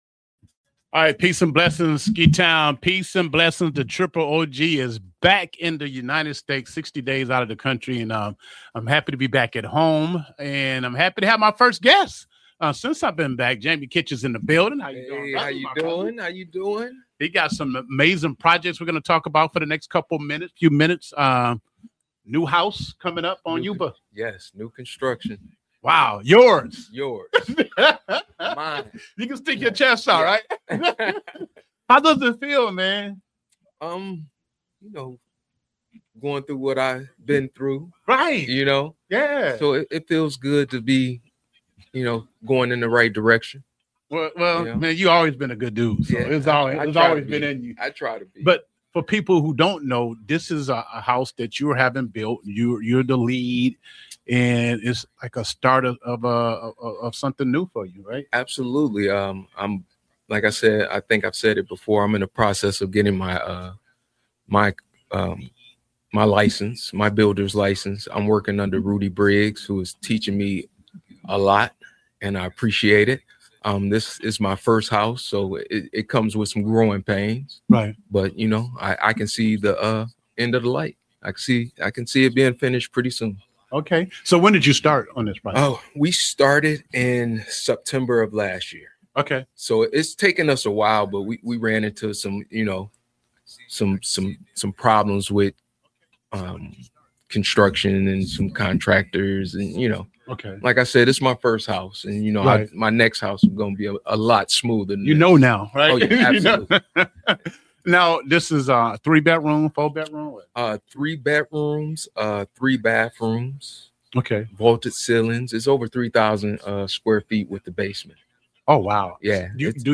Interview with TLK Realtors